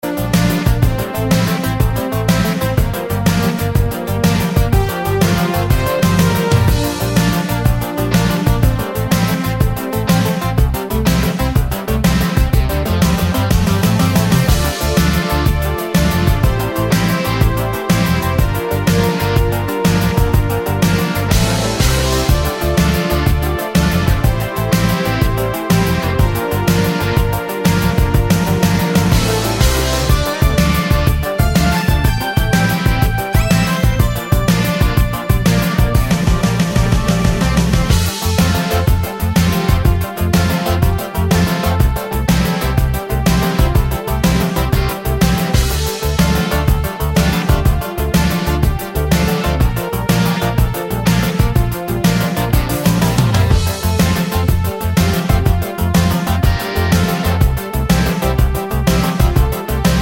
no sax or Backing Vocals Pop (1980s) 4:47 Buy £1.50